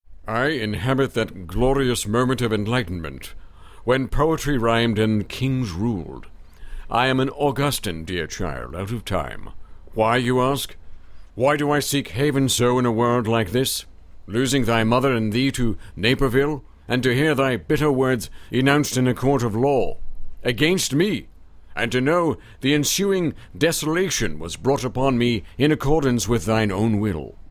pompous_brit_Mstr_21_01.mp3